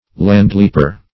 Meaning of landleaper. landleaper synonyms, pronunciation, spelling and more from Free Dictionary.